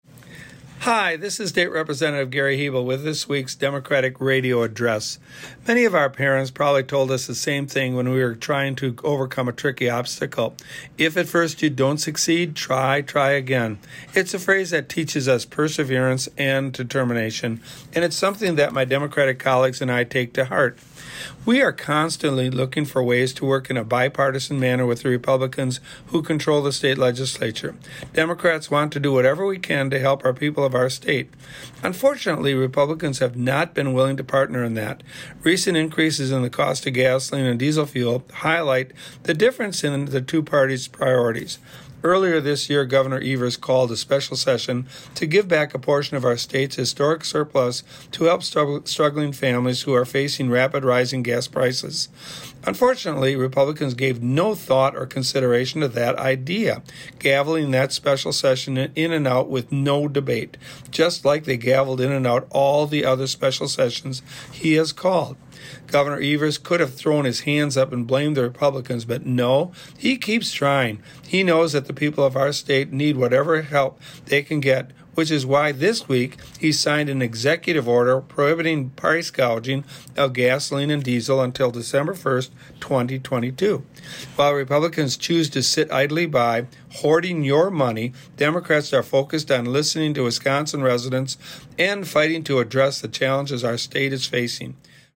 Weekly Dem Radio Address: Rep. Hebl says democratic colleagues continue to try and work in a bipartisan manner with State Republicans - WisPolitics